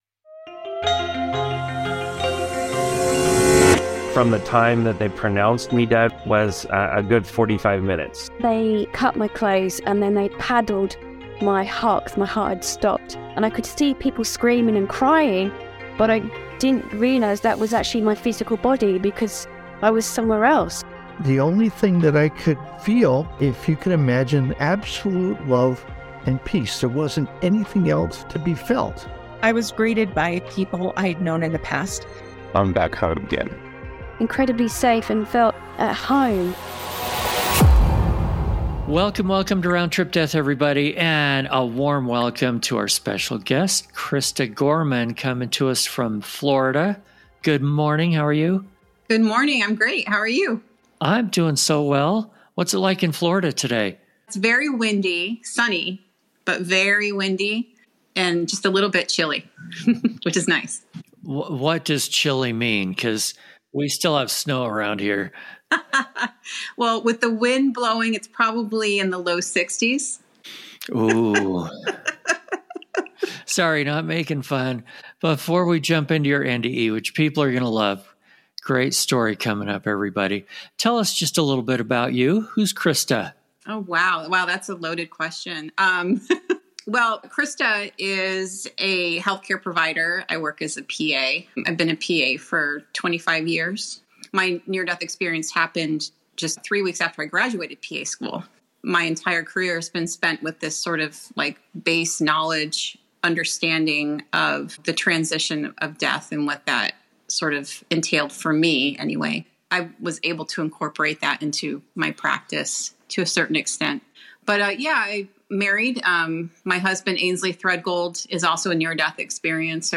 Round Trip Death podcast features discussions with people who have actually died, visited the other side, and returned to talk about it.